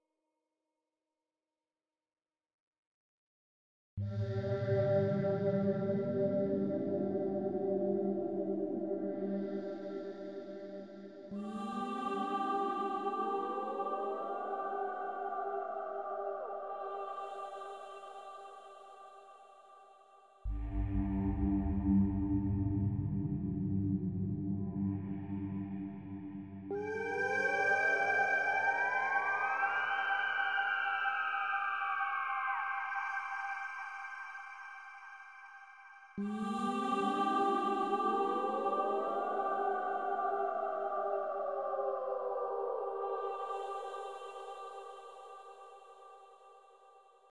I like the sound of some of the airy /breathy voices. The "ah" sounds (formants) are very nice.......
I play a note on the keyboard and get the M1 to "sing" the note I am playing.....but then, as the note lingers on, this other, somewhat subtle but rather disturbing "voice" comes creeping into sound.